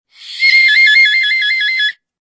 Descarga de Sonidos mp3 Gratis: alarma 12.